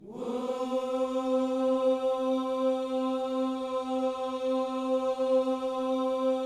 WHOO C 4A.wav